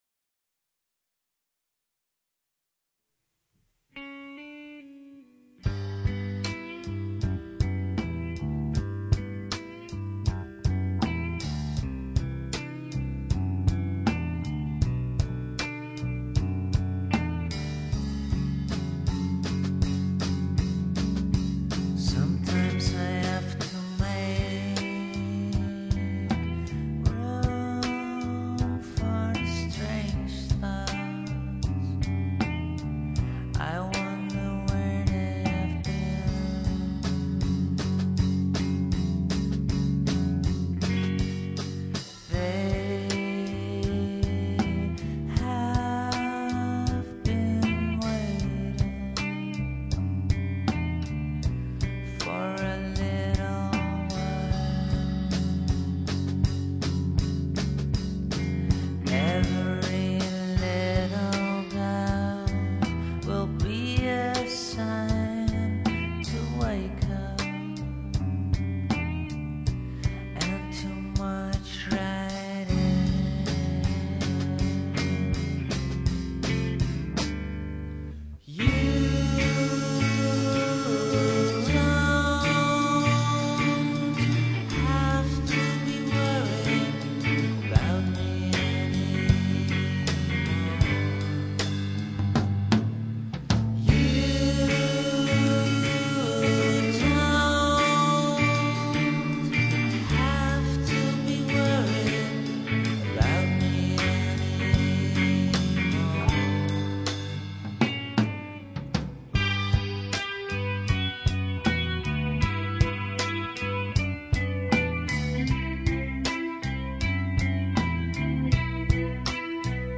where: recorded at CMA (Amsterdam)
trivia: sometimes they rock, sometimes they play jazz!